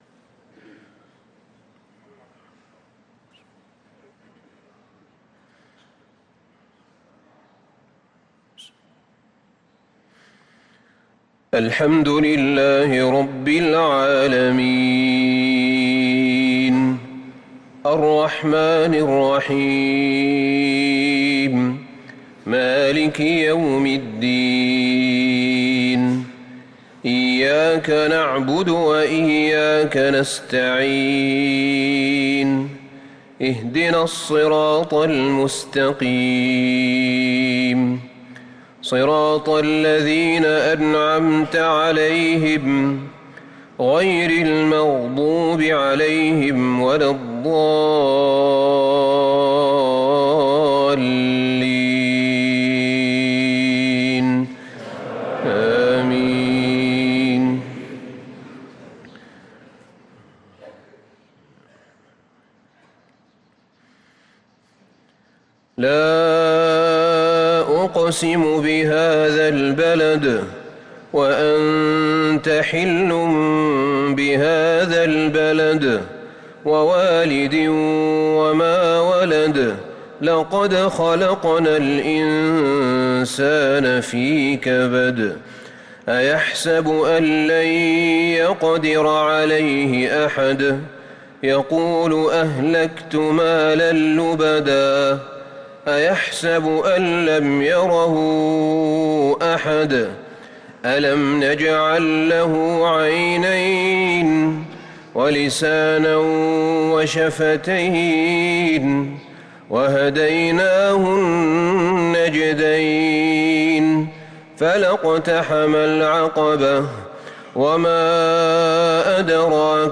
صلاة المغرب للقارئ أحمد بن طالب حميد 15 جمادي الآخر 1445 هـ